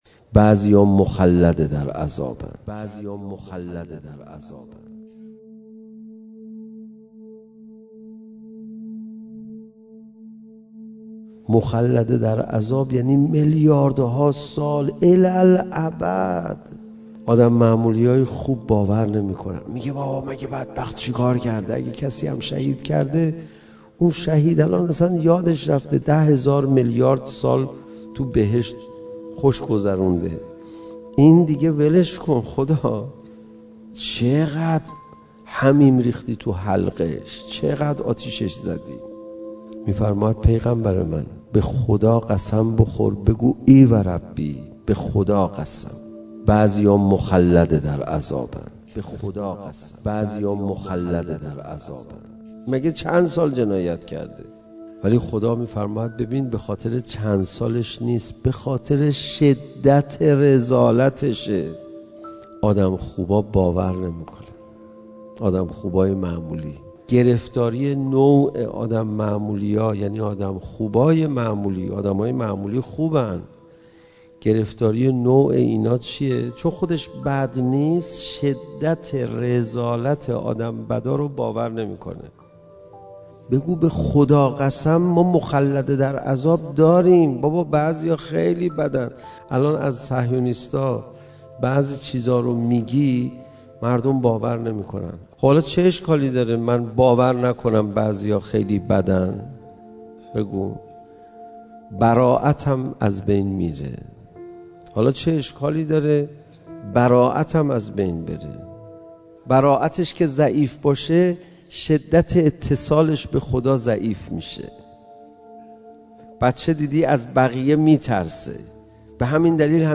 • منبع: کربلا -ورزشگاه المپیک - اربعین98